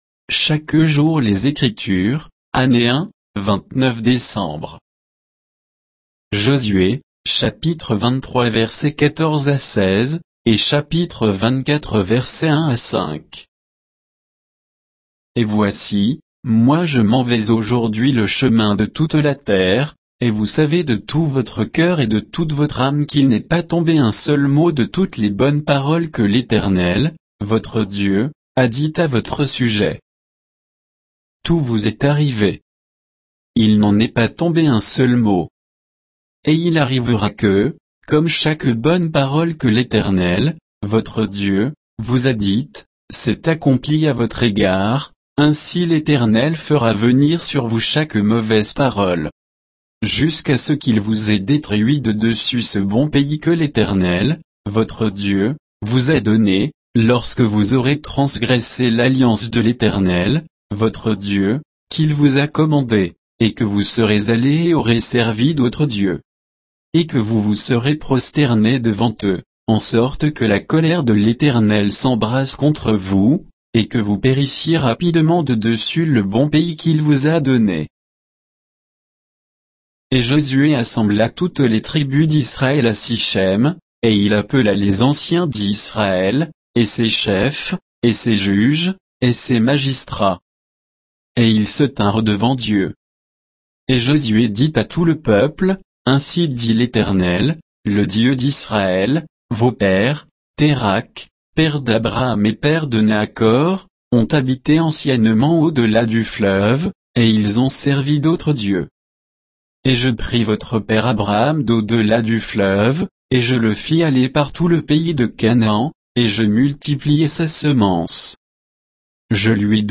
Méditation quoditienne de Chaque jour les Écritures sur Josué 23